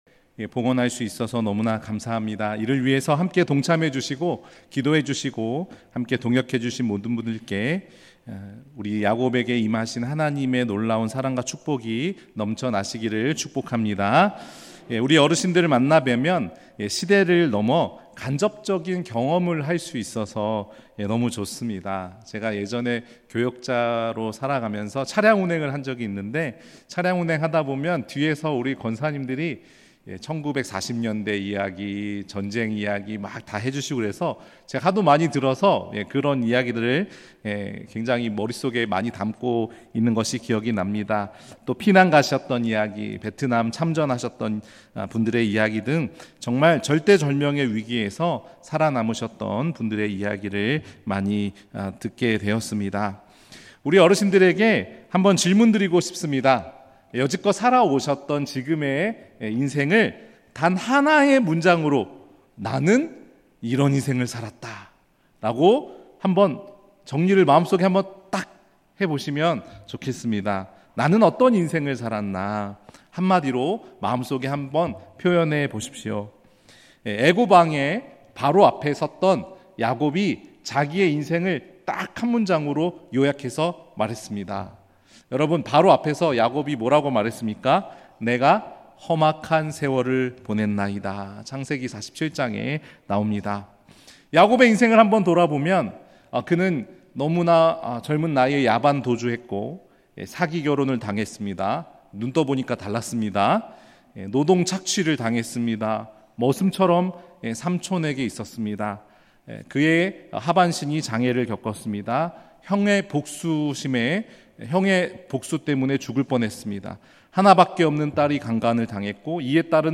2024 7월 7일 주일오후예배 (시니어스쿨 보고예배)
음성설교